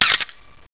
飞鸽叫声：fgs (